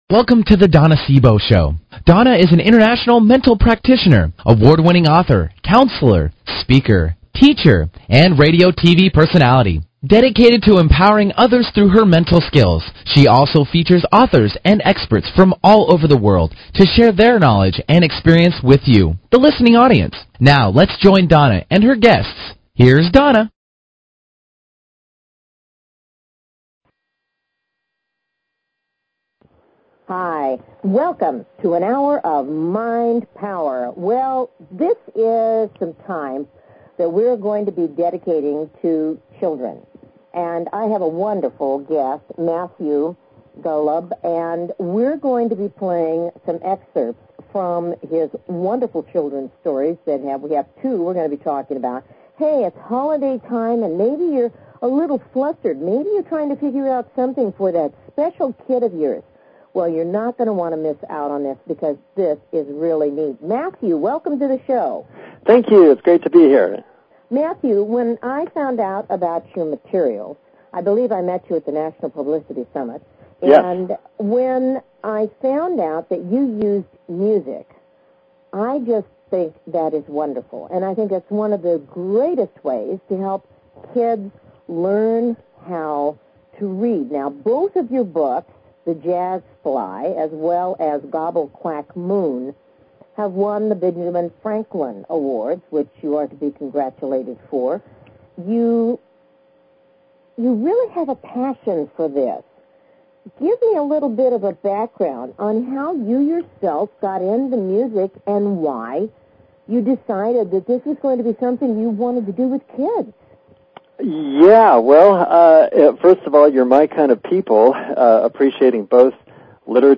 These are wonderful children's books with musical CD's that we'll be playing on the air. Toe tapping and smile provoking fun.